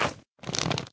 minecraft / sounds / mob / magmacube / jump1.ogg
jump1.ogg